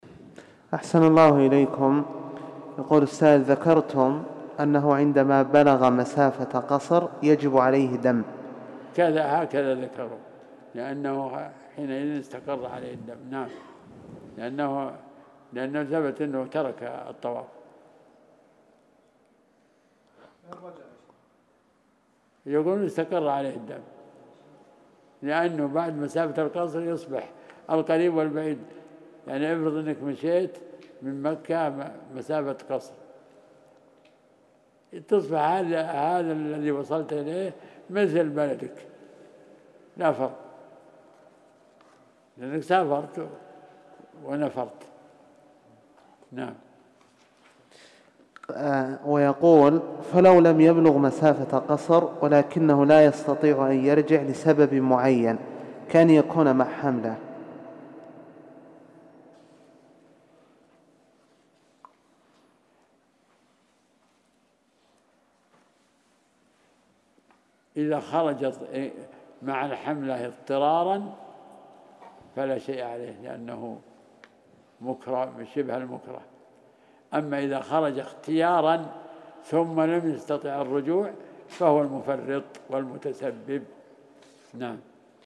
فتاوى الدروسالحجالحج والعمرة